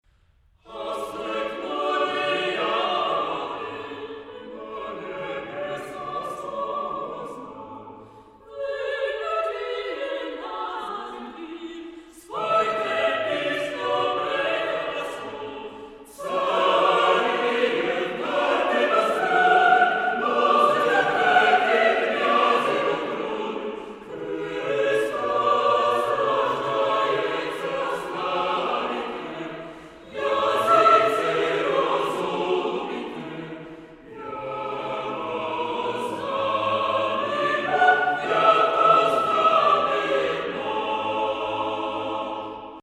Carol ; Sacré ; Orthodoxe
festif ; joyeux ; majestueux
Tonalité : la bémol majeur